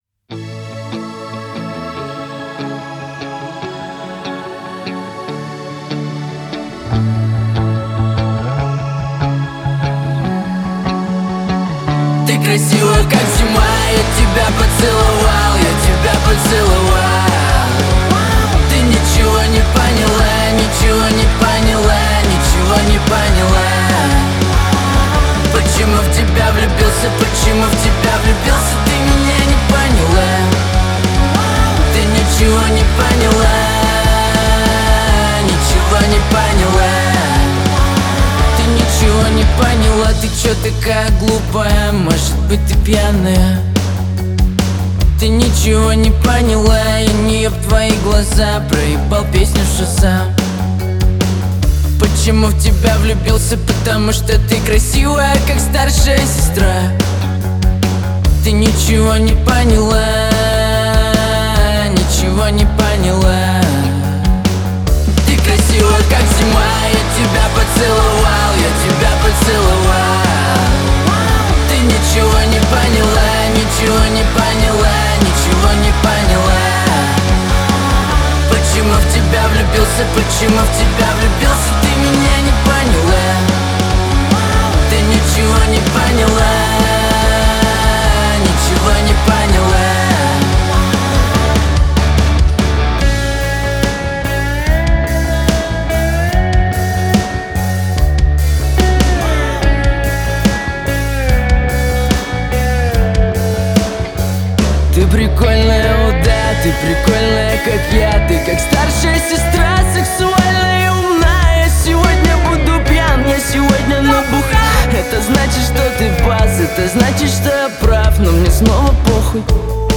Альтернатива